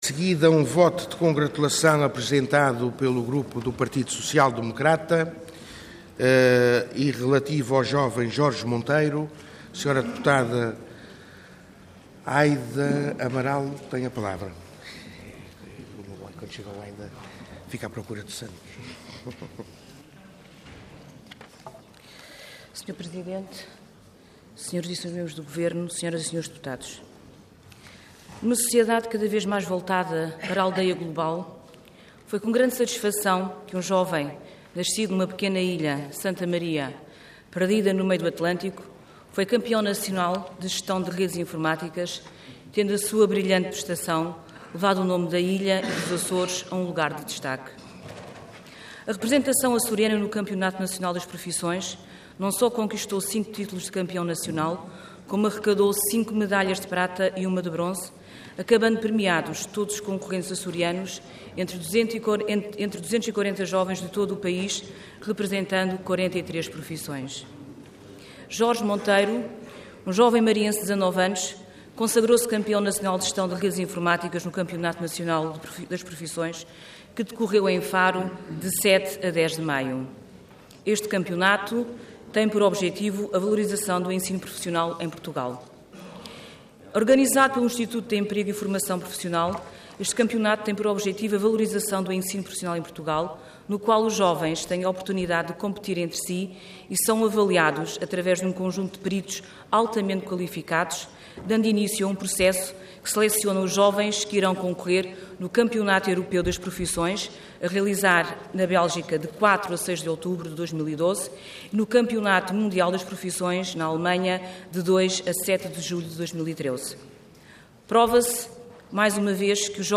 Intervenção Voto de Congratulação Orador Aida Amaral Cargo Deputada Entidade PSD